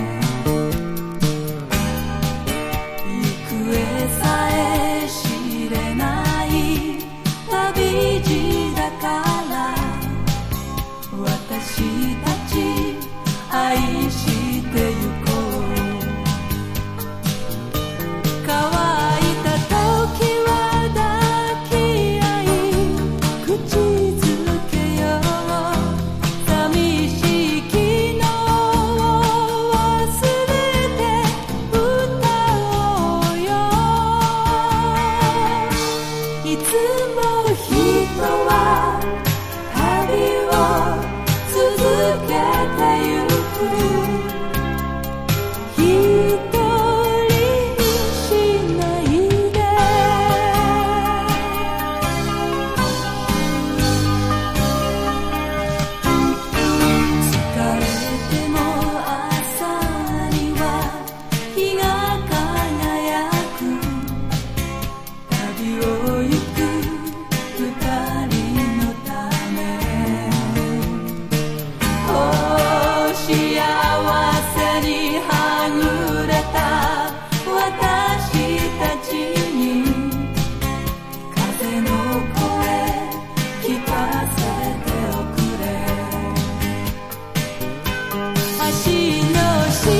SSW / FOLK# CITY POP / AOR# 和モノ